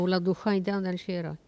Langue Maraîchin
Patois
Catégorie Locution